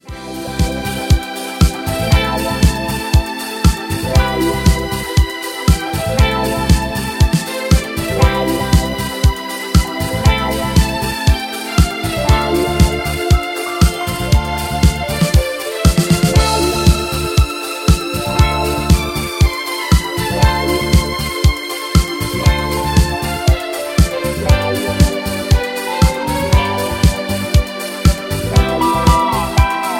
Backing track files: Ital/French/Span (60)
Buy Without Backing Vocals